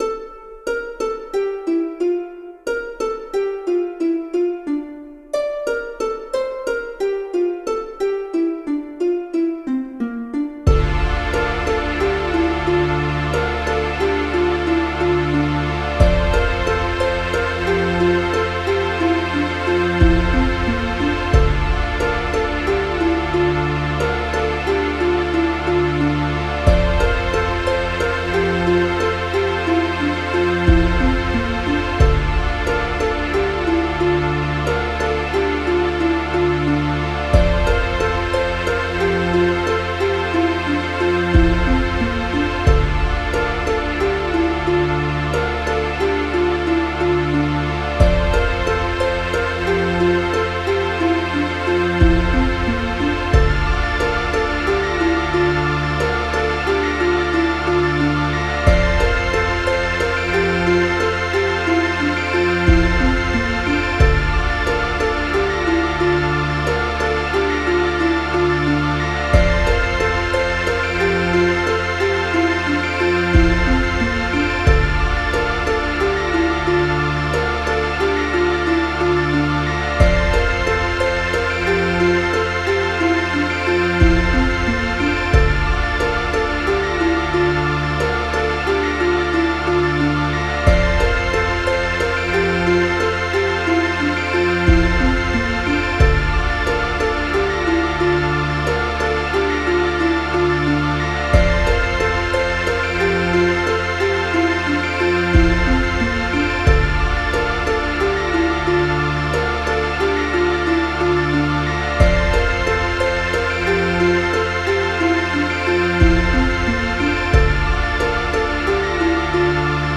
I've been dicking around in BandLab and tried making dungeon synth.